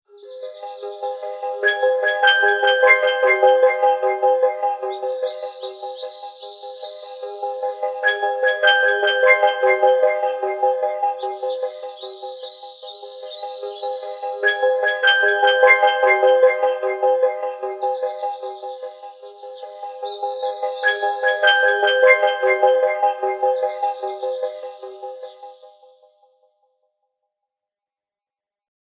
Atmospheric_Forest.ogg